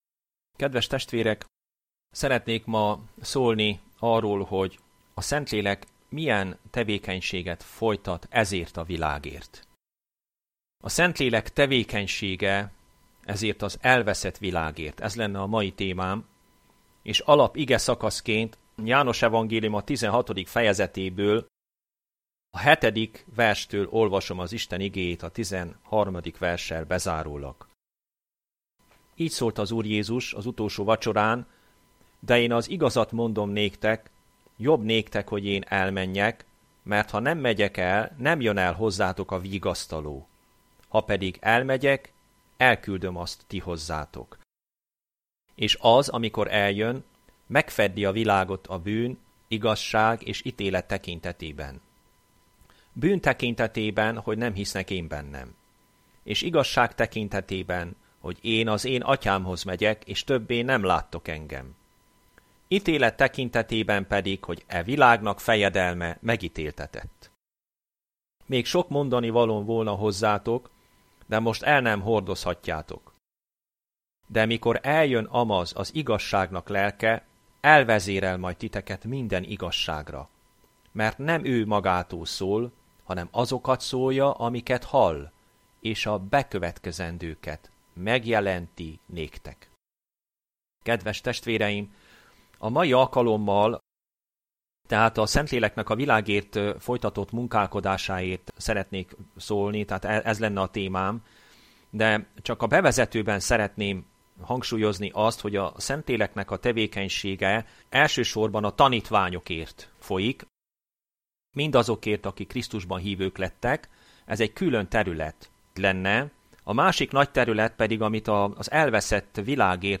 A Szentlélek munkája az elveszett világért Igehirdetések mp3 Az igehirdetéshez kattints ide Hasonló bejegyzések Igehirdetések mp3 Bűnrendezésről.